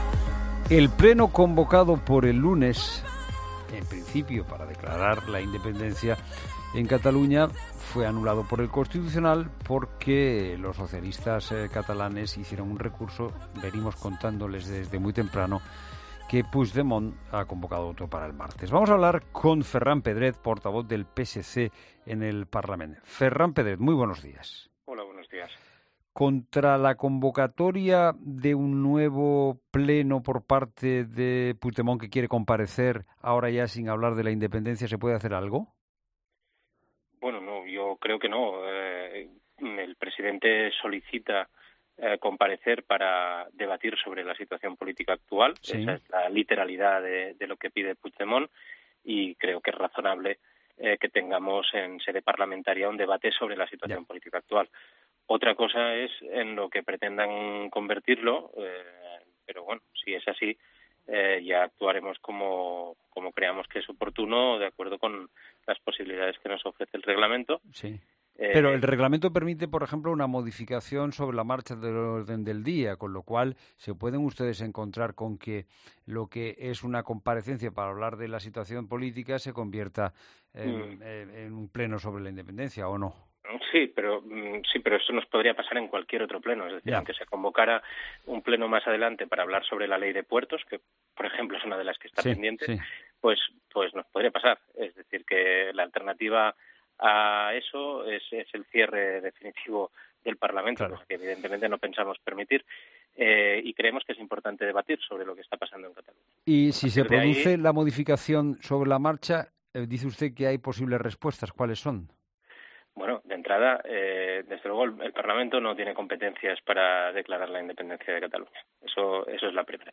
Entrevista política